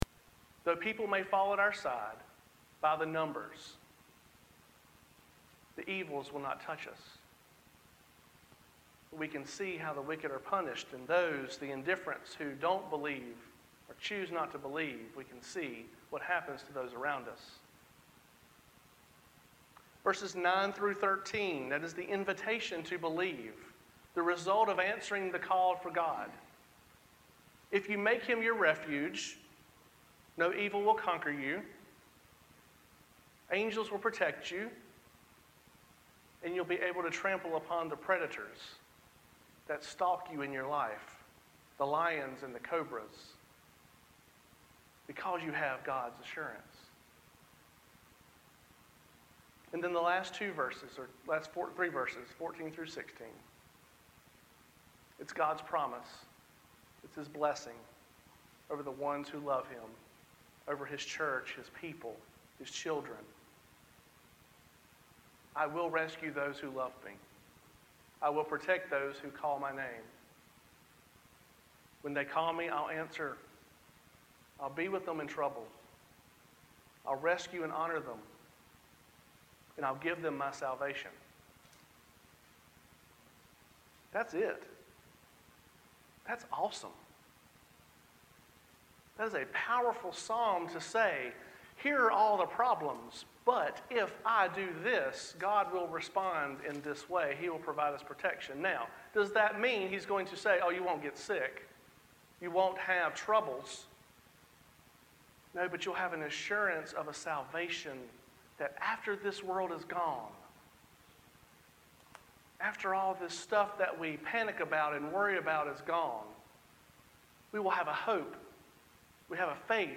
The Audio is in two parts due to a recording difficulty on the Sound System.
Passage: Psalm 91 Service Type: Sunday Worship The Audio is in two parts due to a recording difficulty on the Sound System.